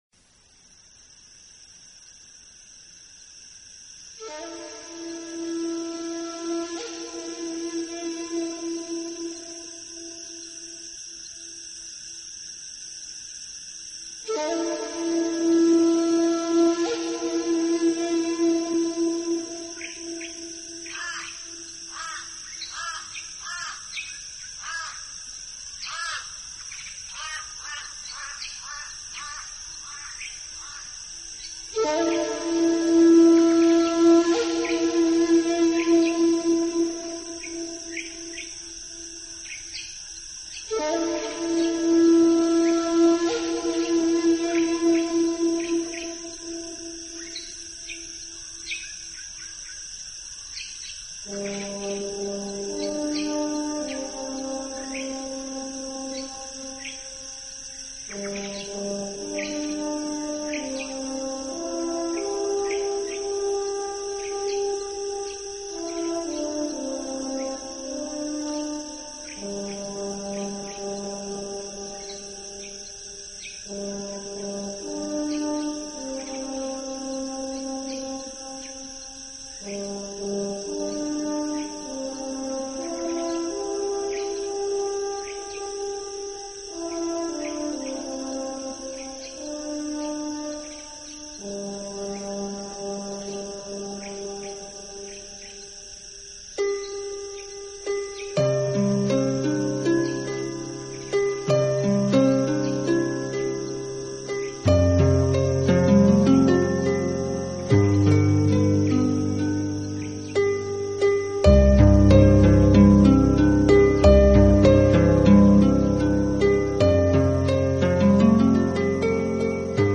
【新世纪纯音乐】
专辑语言：纯音乐